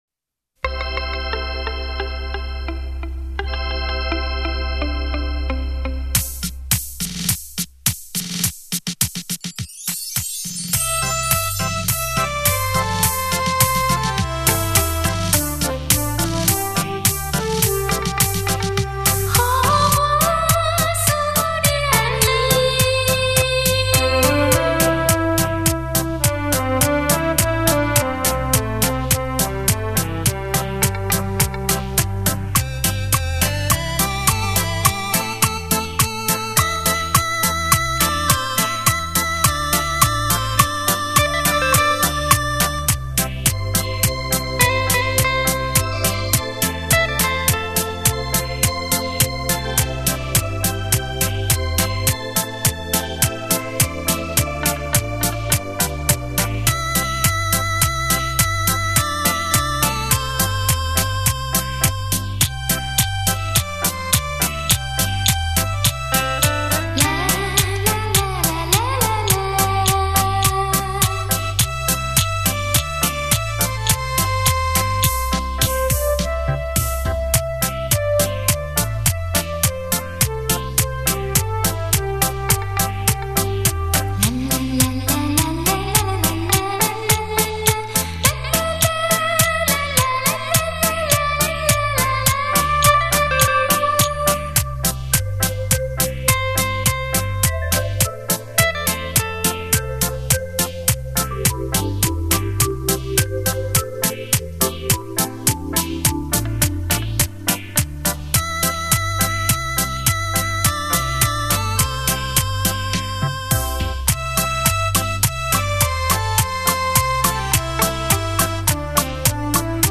专辑类型：电子琴音乐
超立体现场演奏
曲曲精選 曲曲動聽，电子琴音域较宽，和声丰富，
另外，电子琴还安装有混响回声，延长音、震音和颤音等
精心打造完美电子音乐，立體效果 環繞身歷聲 超魅力出擊，